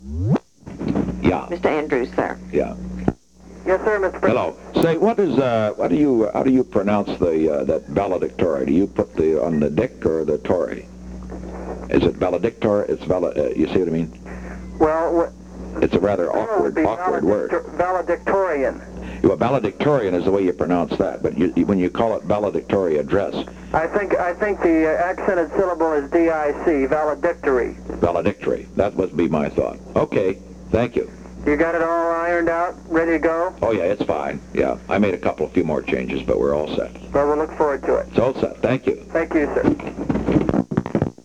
Secret White House Tapes
Conversation No. 6-135
Location: White House Telephone